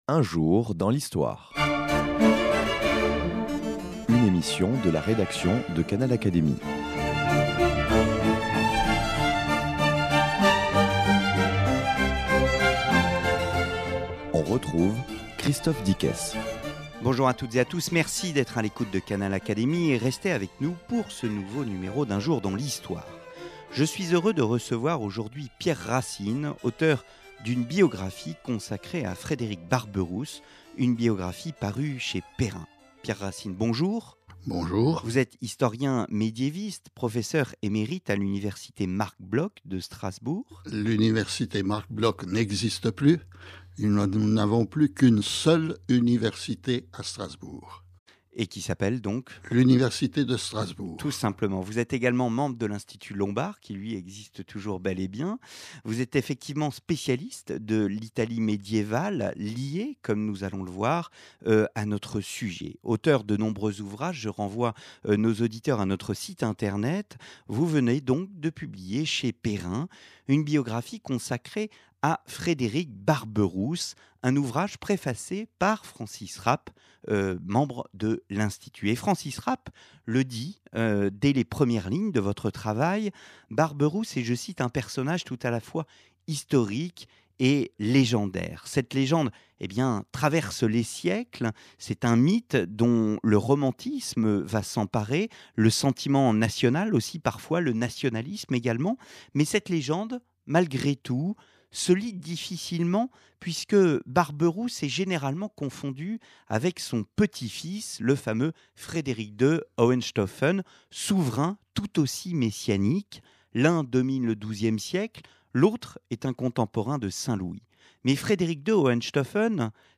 Spécialiste de l’Italie médiévale, notre invité